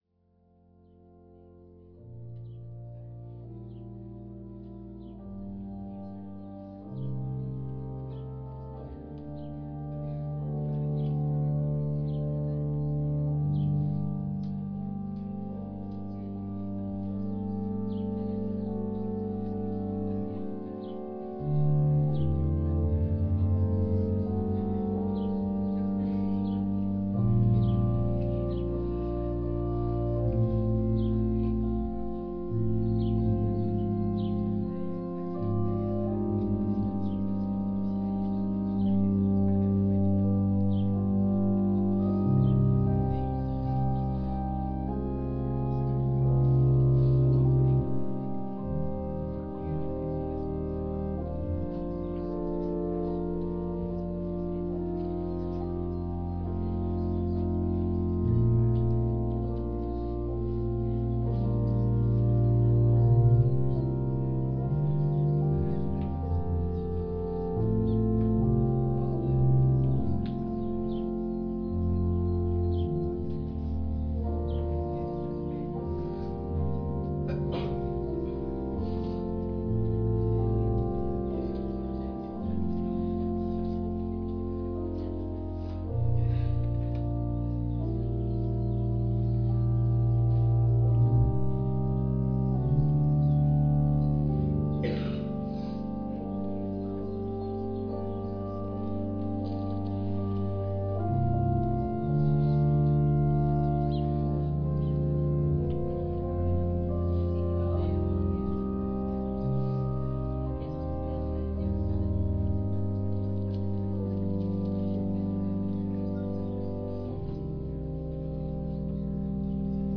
Locatie: Gortel